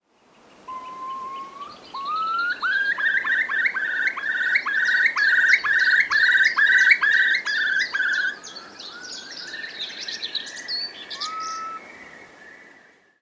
Art: Storspove (Numenius arquata)
Lyd i fluktspill